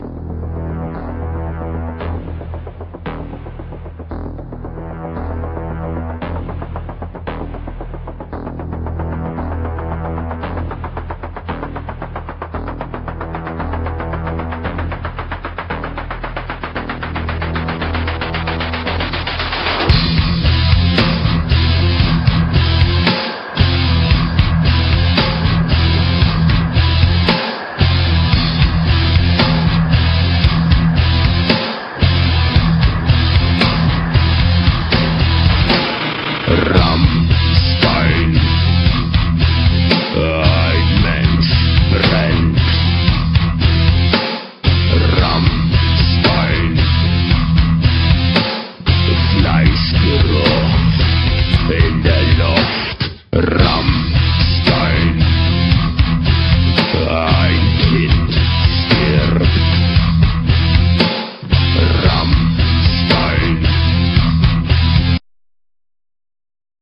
metal
they have a heavy, machine like rythem
and growling vocals.